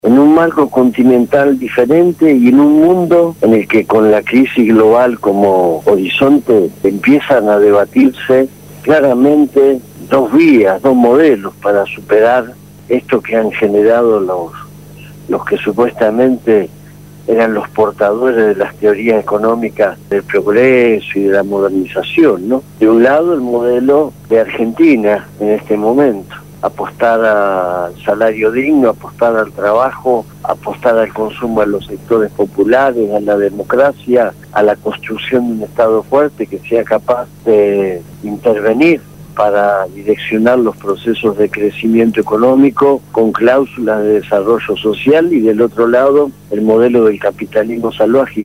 Entrevista a Hugo Yasky, Secretario General CTA de los Trabajadores